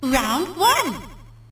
snd_boxing_round1_bc.wav